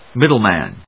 音節míddle・màn 発音記号・読み方
/ˈmɪdʌˌlmæn(米国英語)/